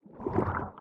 Minecraft Version Minecraft Version 25w18a Latest Release | Latest Snapshot 25w18a / assets / minecraft / sounds / mob / squid / ambient3.ogg Compare With Compare With Latest Release | Latest Snapshot
ambient3.ogg